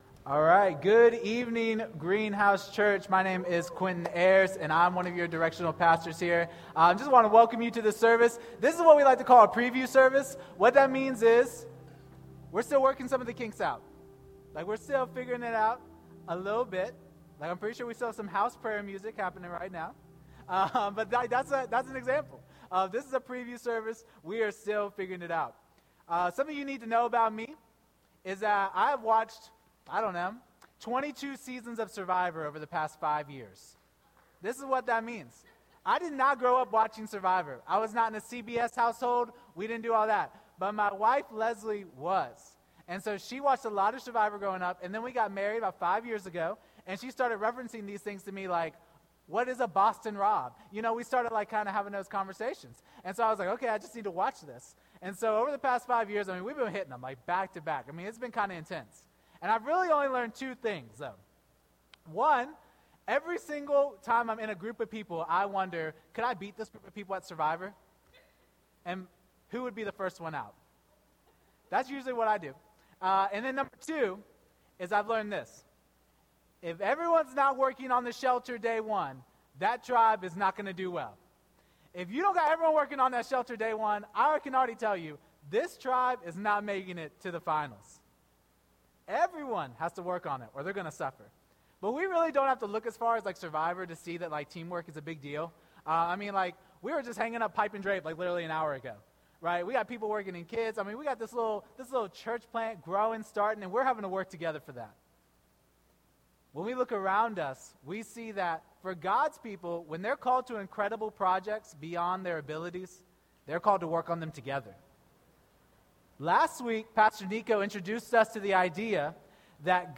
This sermon was delivered on June 8th, 2025.